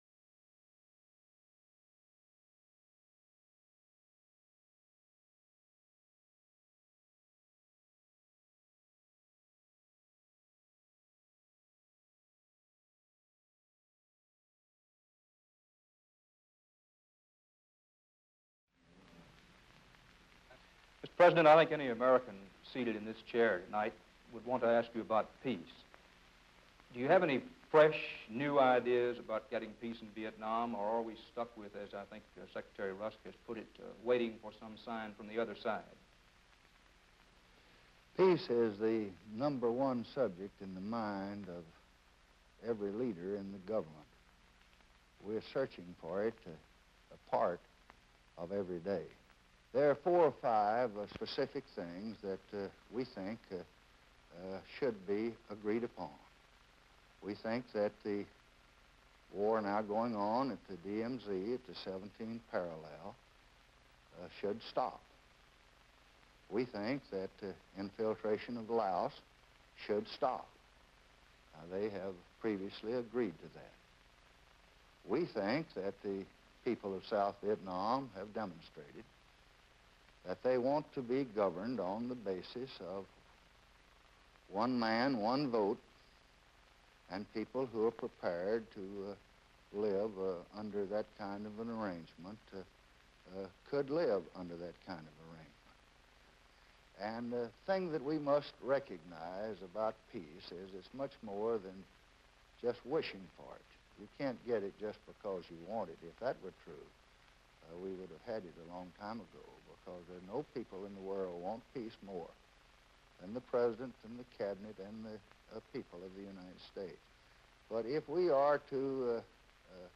December 19, 1967: A Conversation with President Lyndon Johnson